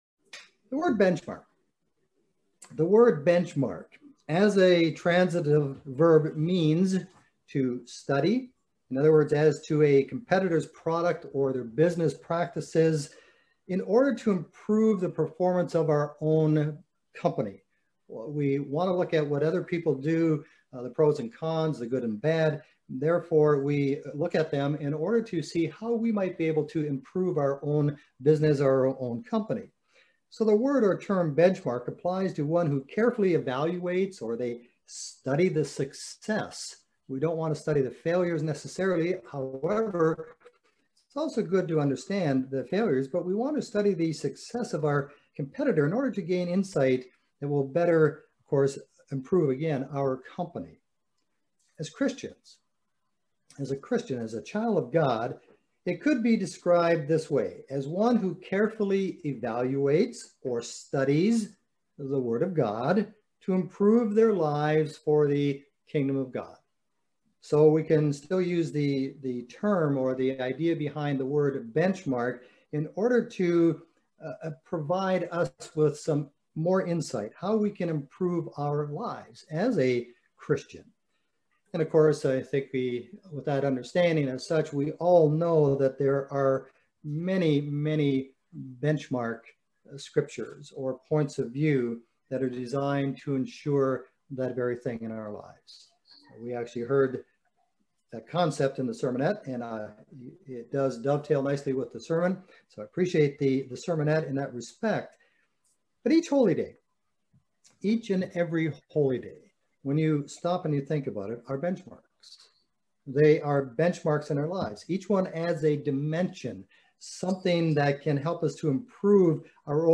Sermons
Given in Lexington, KY